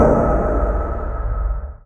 clang.wav